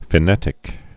(fĭ-nĕtĭk)